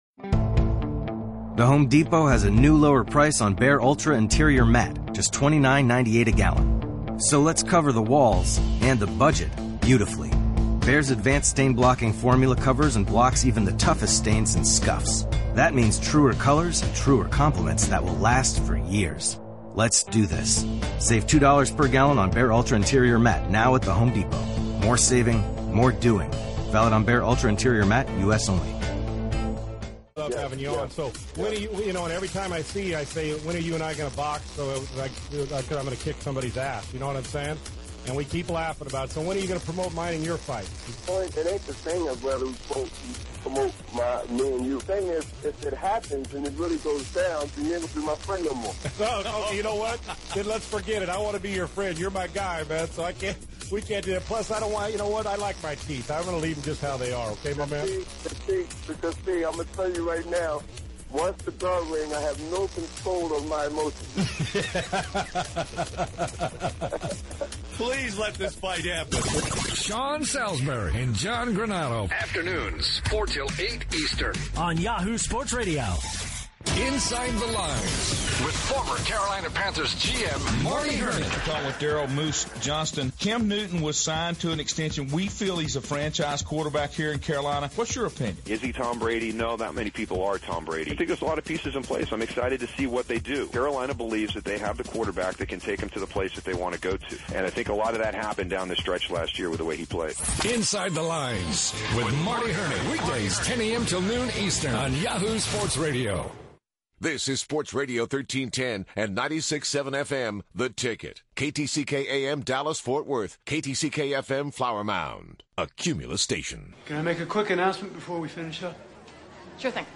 played some songs and talked about growing up in Flower Mound